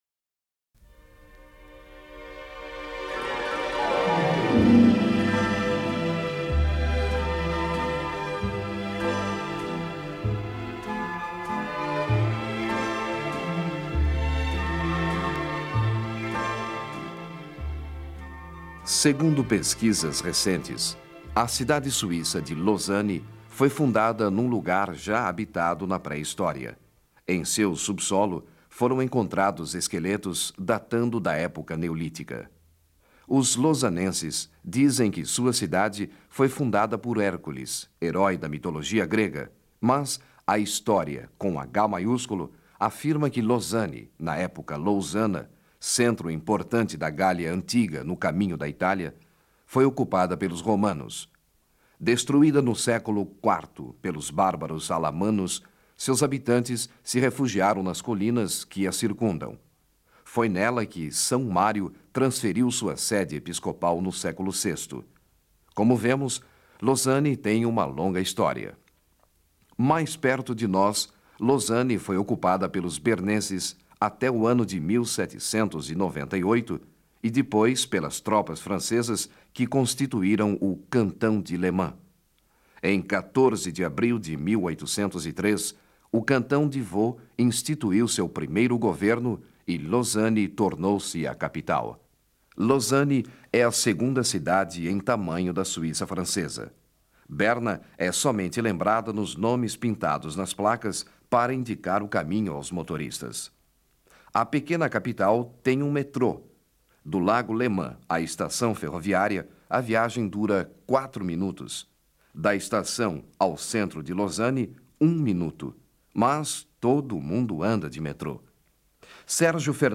Ao vivo de Lausanne...
Programa da Rádio Suíça Internacional (RSI) sobre Lausanne transmitido em 1977